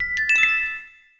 camcorder_start_musical02.wav